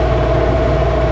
truck engine.wav